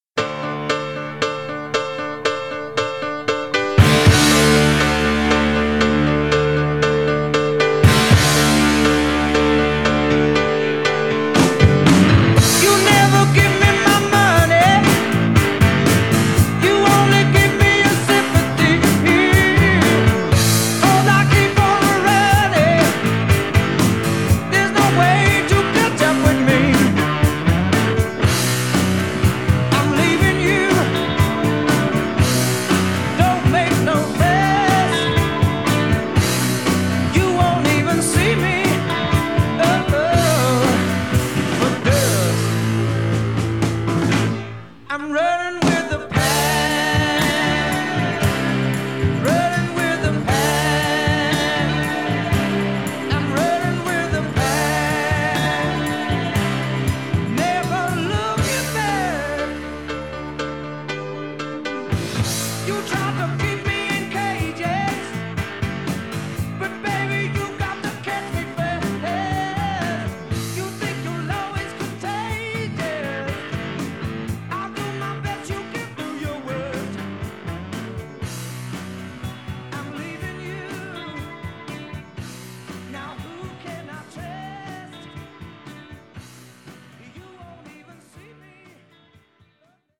ups the tempo enough for that steady run